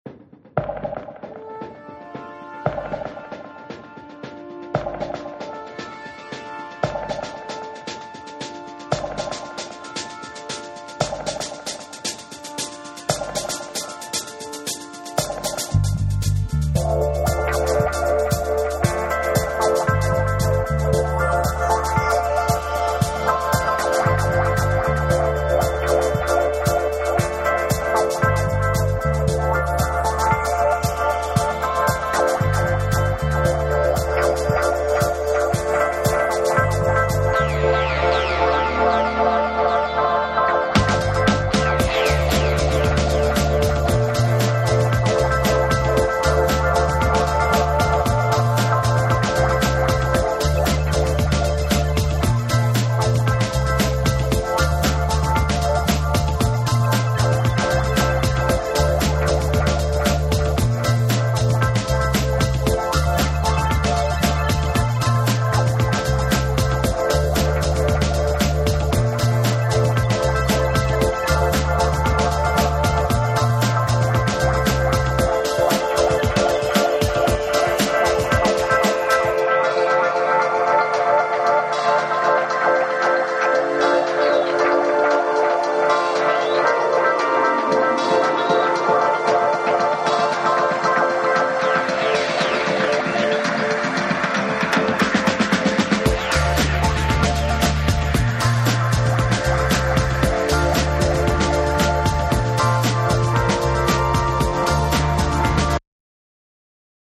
BREAKBEATS / REGGAE & DUB / CD